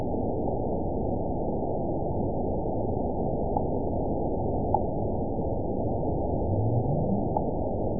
event 912499 date 03/28/22 time 00:29:13 GMT (3 years, 1 month ago) score 9.56 location TSS-AB03 detected by nrw target species NRW annotations +NRW Spectrogram: Frequency (kHz) vs. Time (s) audio not available .wav